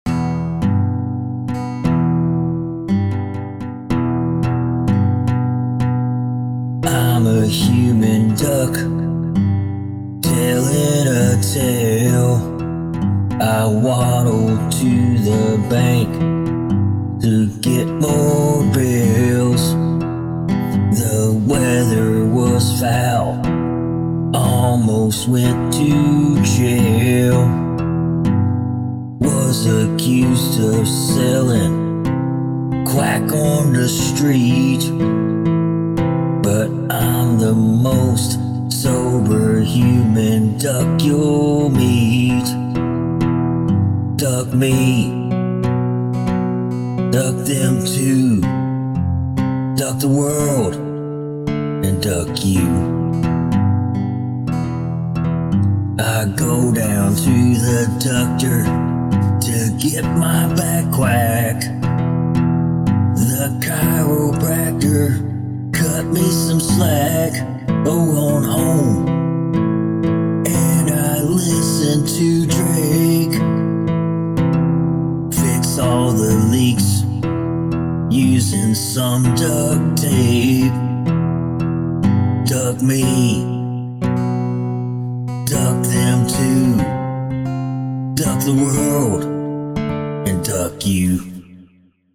I love the puns and jokes, and it’s a fun short song!
I do try fore that Tom Waits gruff sometimes.